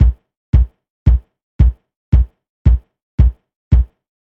When it comes to the Club Hits that Timbo produces, he usually has his Bass Drum hitting on every single beat.
Bass Drum Example
Bass_Drum_Example_1.mp3